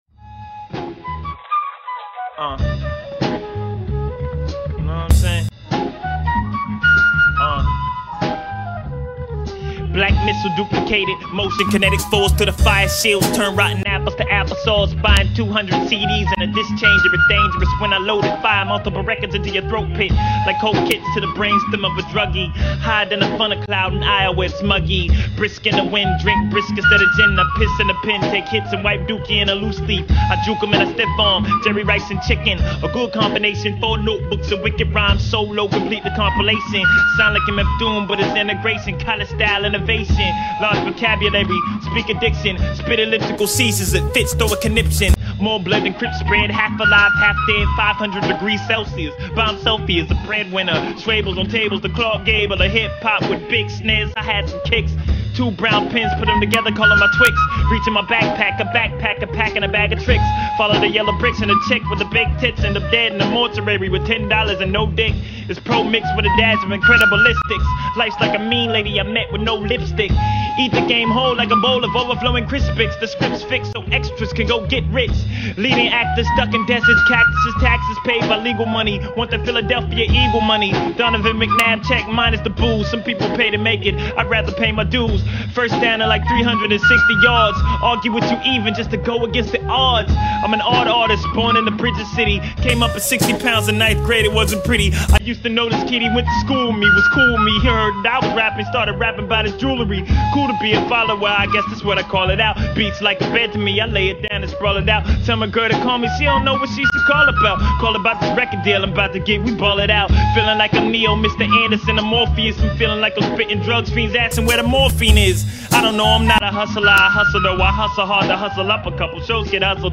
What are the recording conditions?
Dead End Hip Hop Interview LIVE on Blog Talk Radio!